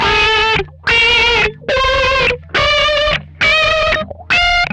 MANIC RISE 2.wav